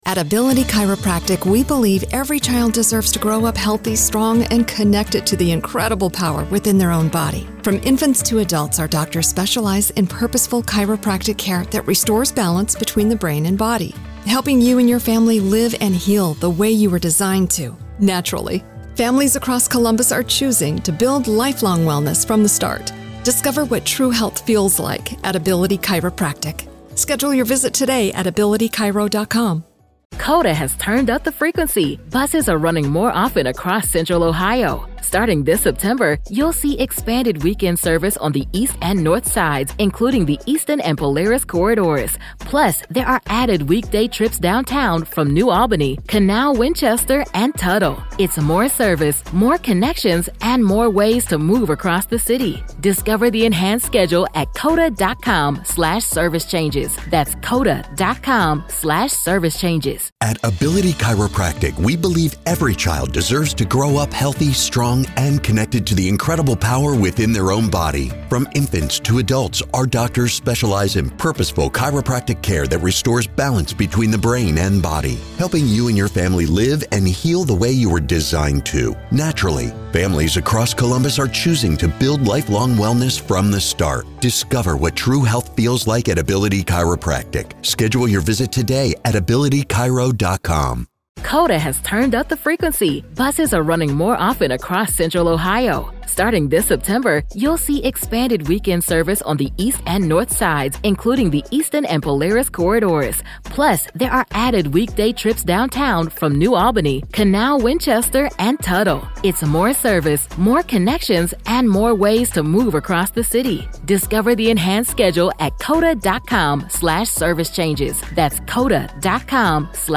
This conversation asks the questions prosecutors and judges won’t: What message does this send to survivors?